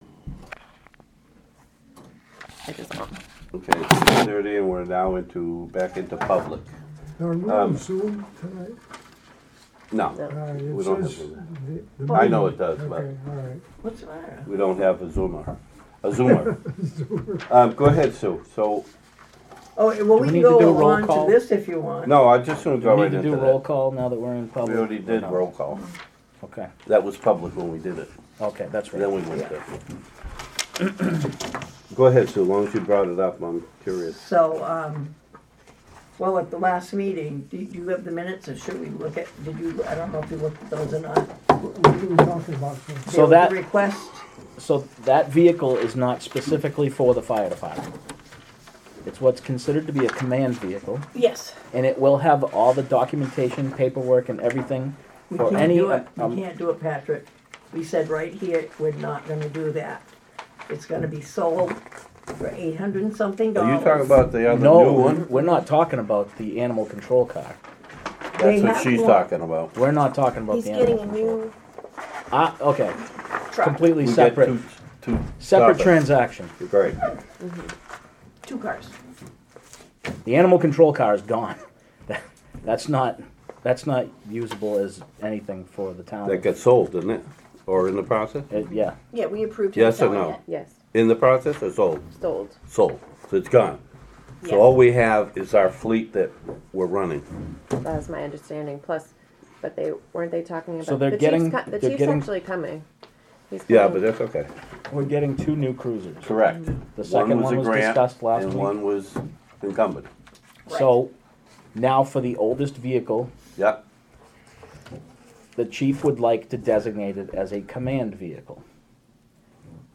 Audio recordings of committee and board meetings.
Board of Selectmen Meeting